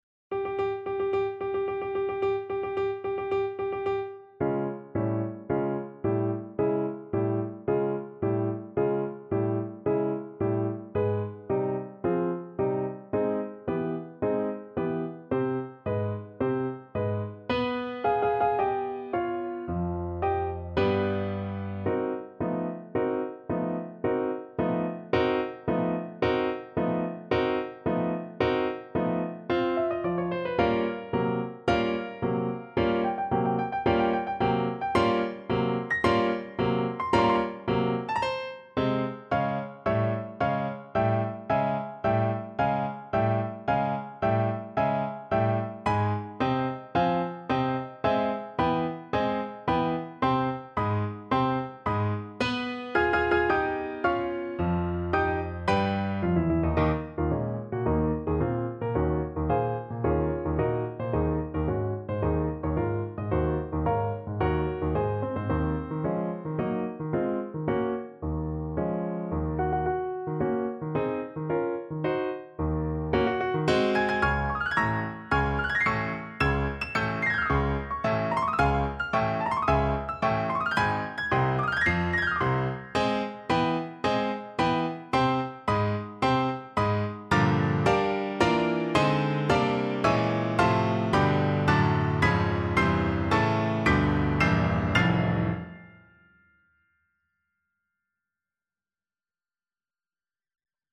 4/4 (View more 4/4 Music)
~ = 110 Tempo di Marcia
Classical (View more Classical Flute Music)